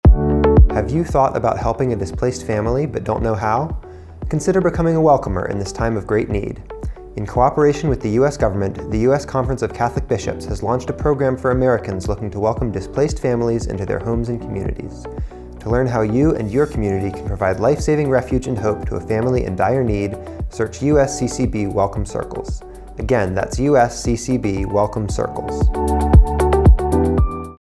30 Second PSA
Welcome Circles 30 Male_1.mp3